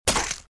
SFX刀刺穿声素材音效下载
SFX音效